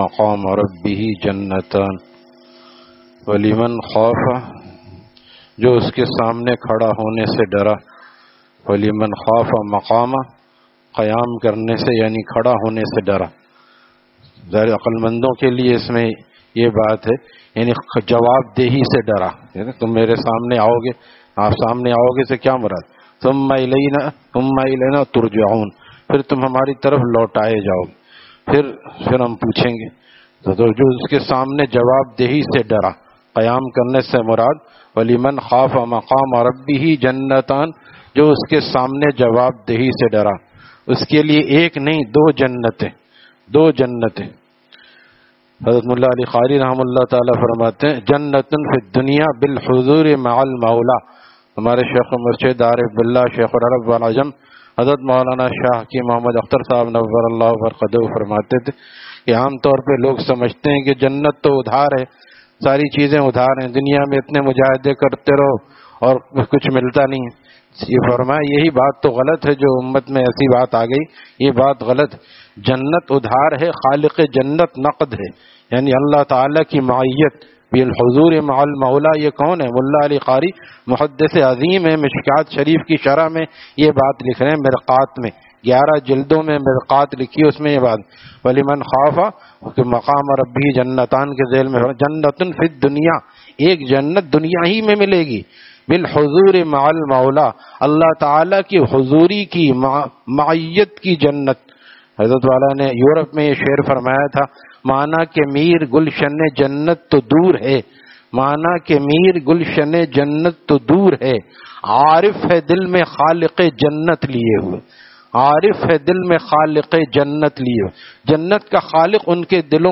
Taleem After Fajar at Jama Masjid Gulzar e Muhammadi, Khanqah Gulzar e Akhter, Sec 4D, Surjani Town